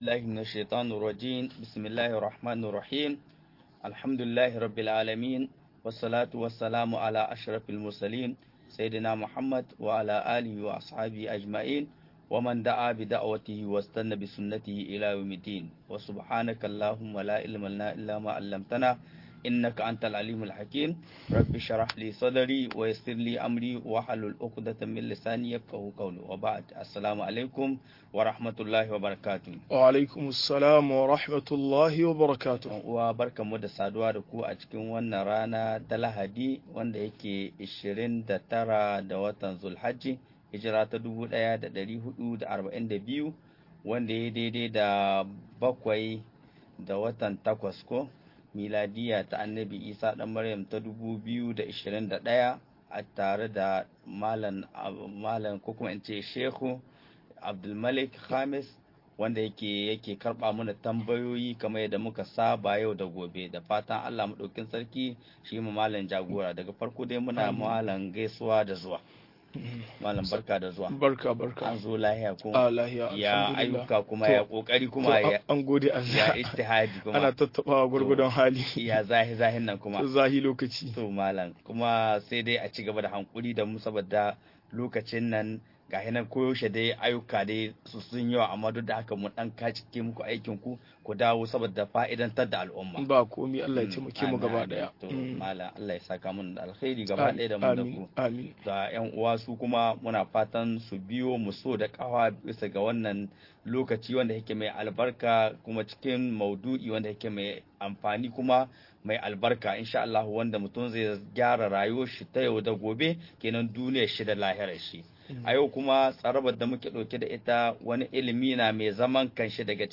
Ka'idodin ilimin fiqhu - MUHADARA